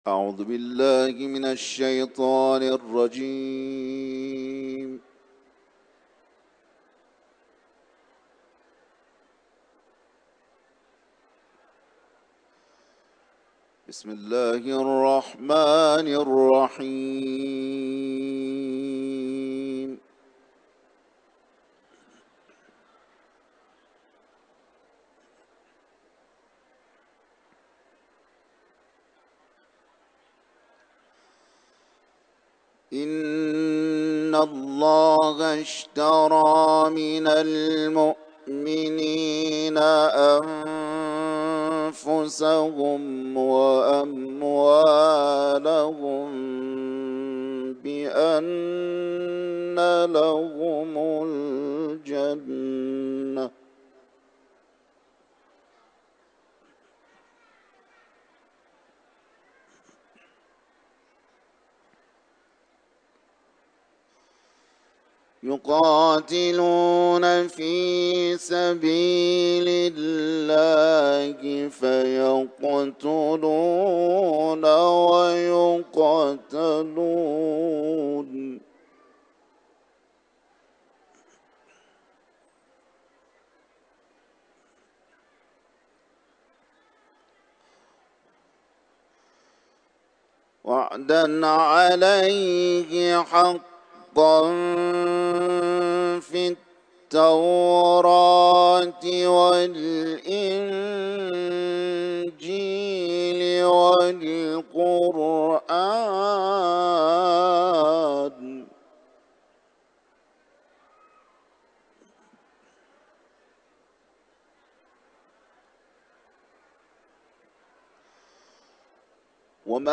Etiketler: kuran ، tilavet ، İranlı kâri ، Kuran kârisi ، Kuran okumak